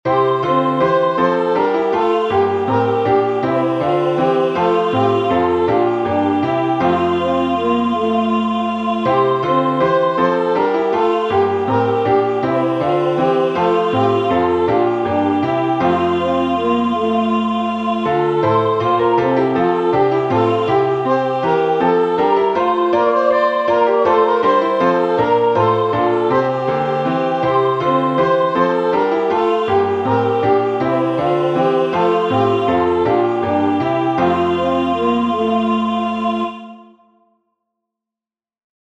Acclamation (UK 24)The Spirit of the Lord